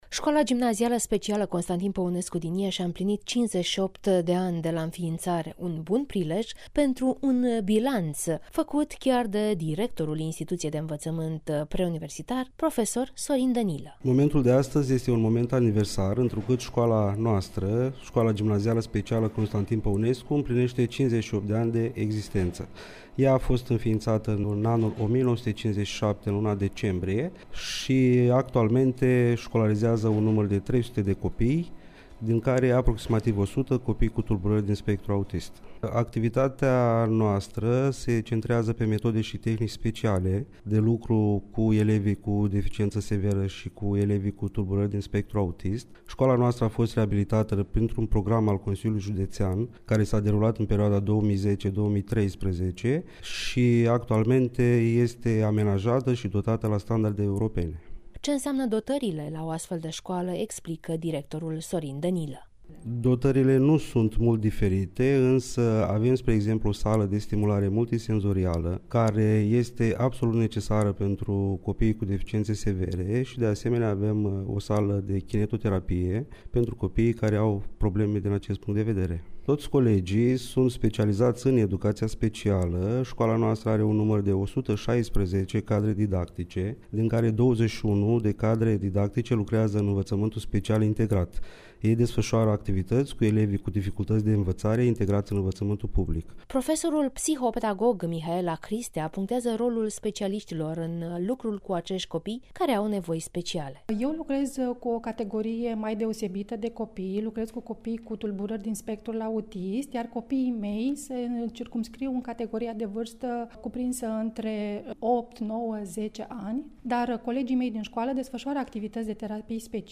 (REPORTAJ) Dotări de ultimă generație la Şcoala Gimnazială Specială “Constantin Păunescu” Iaşi